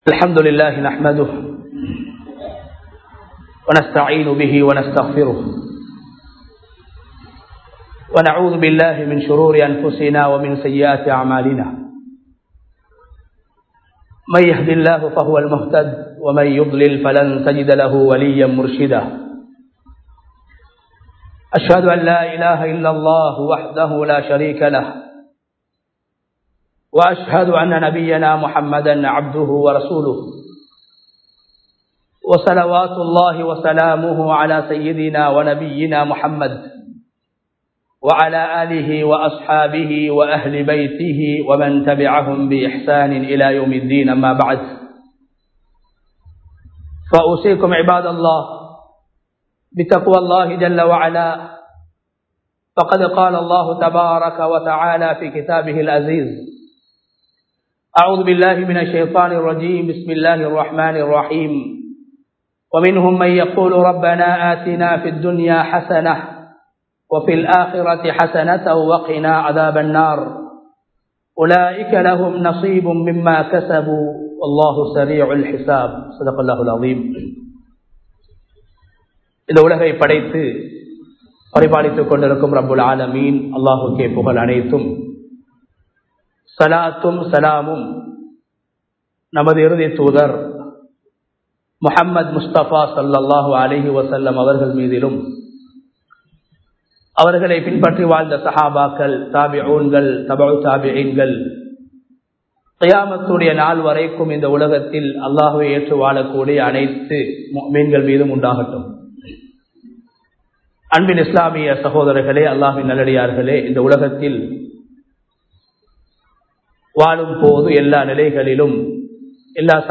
அல்லாஹ்விடம் பாதுகாப்புத் தேடுவோம் | Audio Bayans | All Ceylon Muslim Youth Community | Addalaichenai
Minuwangoda, Galoluwa Jumua Masjidh 2025-06-13 Tamil Download